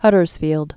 (hŭdərz-fēld)